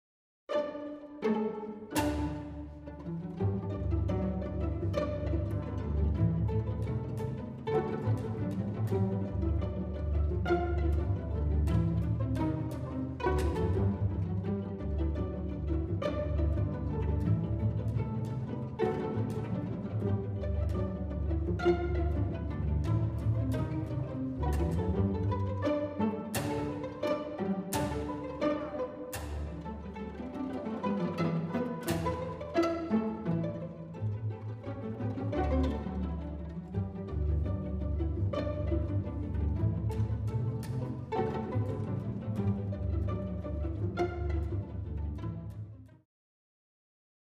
Orchestre seul